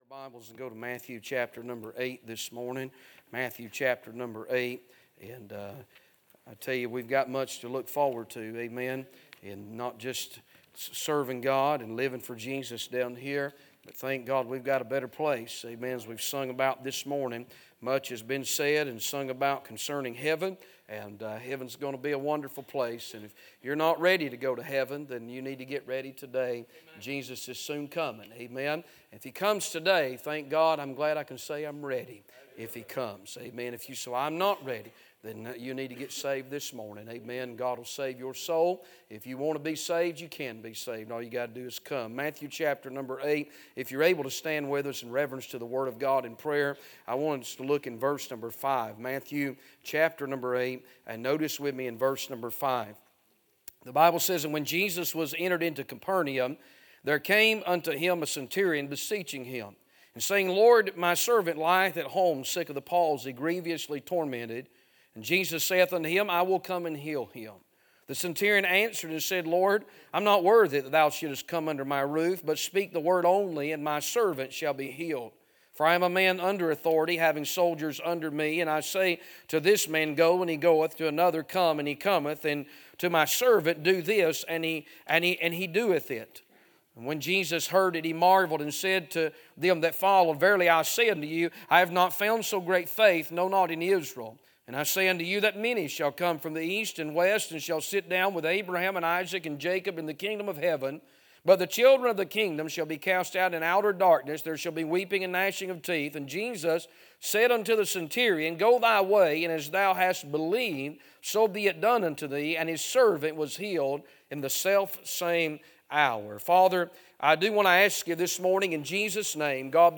A sermon preached Sunday Morning, on May 11, 2025.